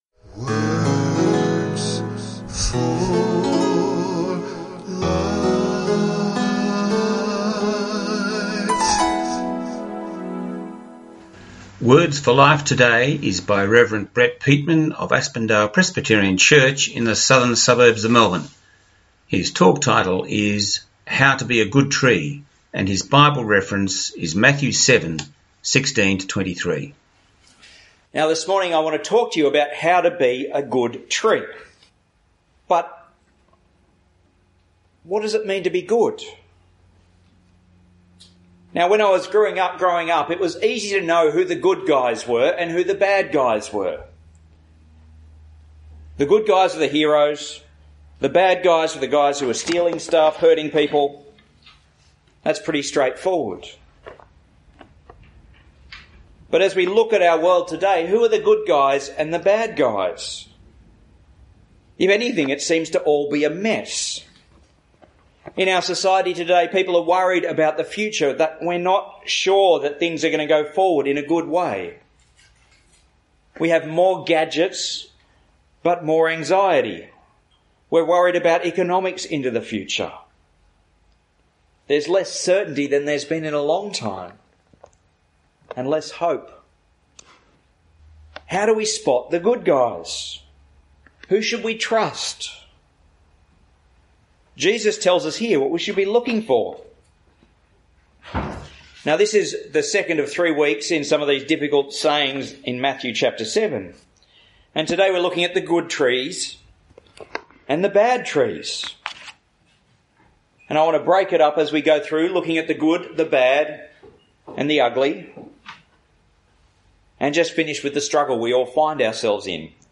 Our Songs of Hope Christian sermon, broadcast this morning, 9Feb20